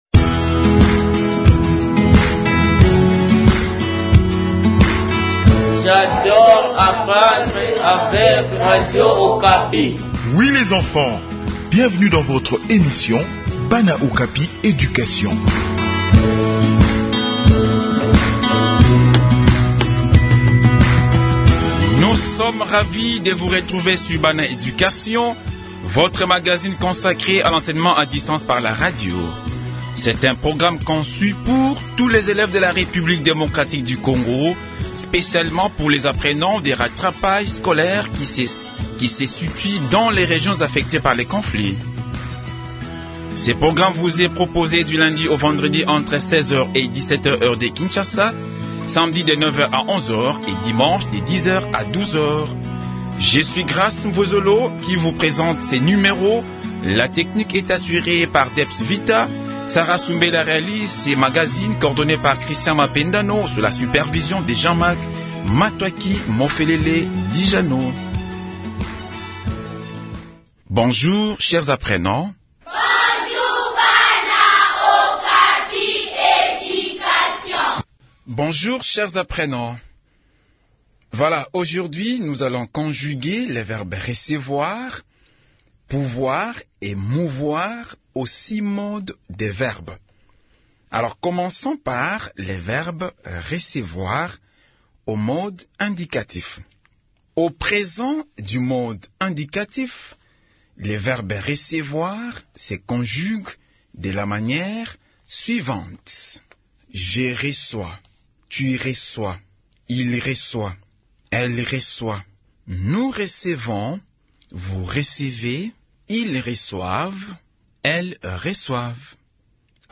Réécoutez cette leçon pour enrichir votre français et affiner votre expression.